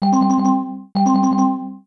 Announcement.wav